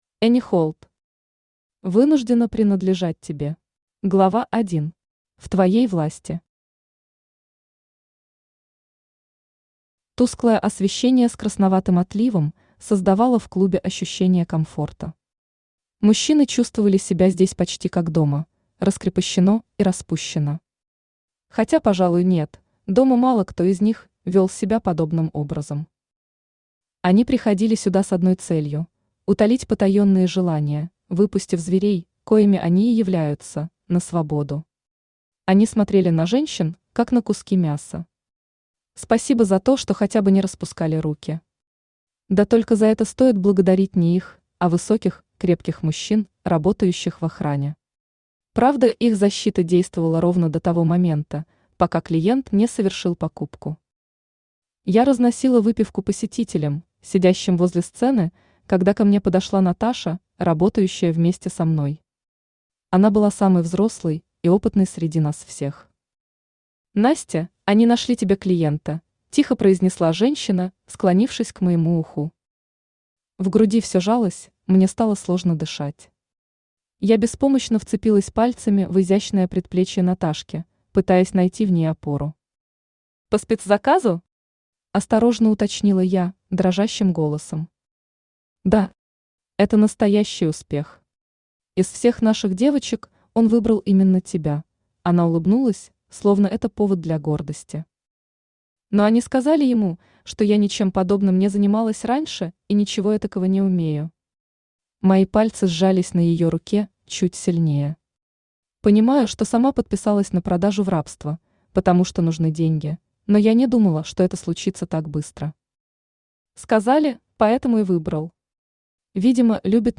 Аудиокнига Вынуждена принадлежать тебе | Библиотека аудиокниг
Aудиокнига Вынуждена принадлежать тебе Автор Энни Холт Читает аудиокнигу Авточтец ЛитРес.